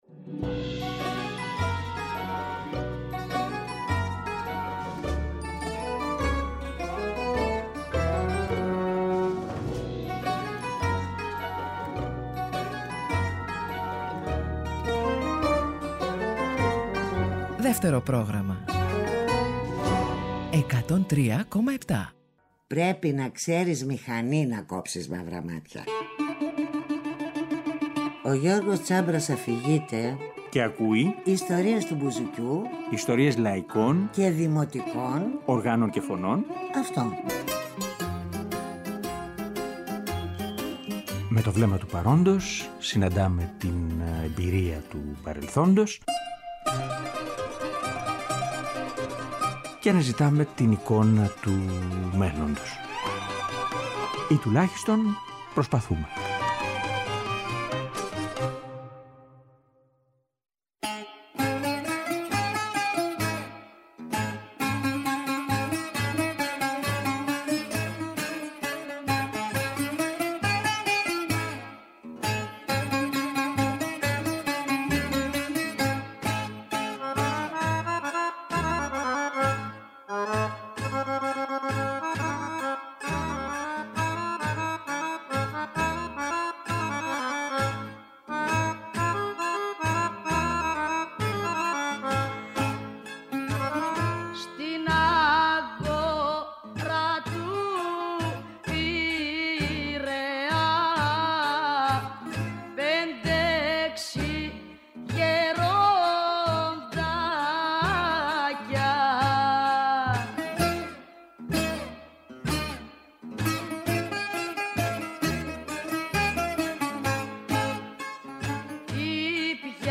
Στην εκπομπή, ακούμε τραγούδια από το «ξεκίνημα» και διαβάζ